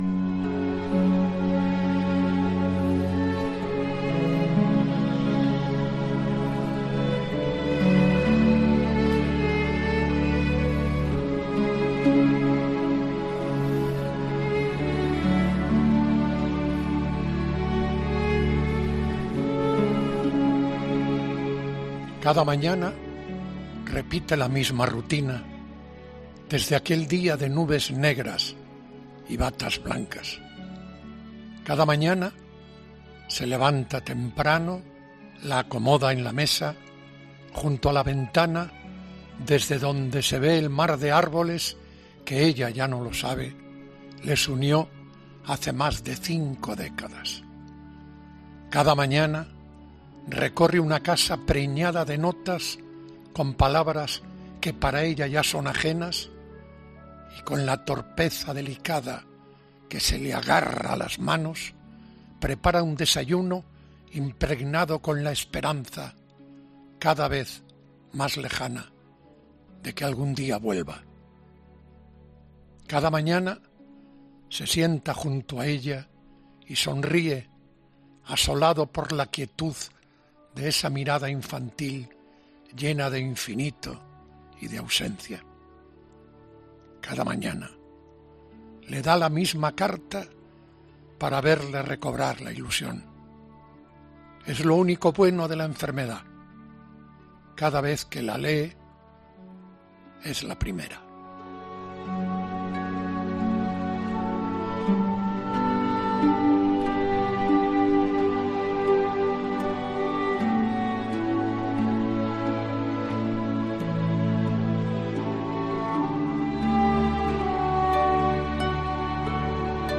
Este viernes se ha celebrado el Día Mundial del Alzhéimer y en 'La Linterna' ocurría algo muy especial. Pepe Domingo Castaño ponía voz a los enfermos de Alzhéimer, con el poema de Sergio de la Marta.